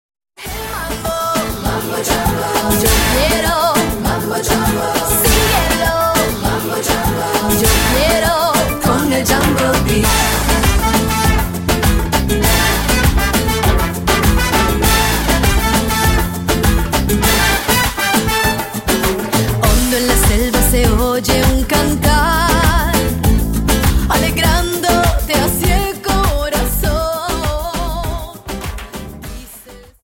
Dance: Samba 50s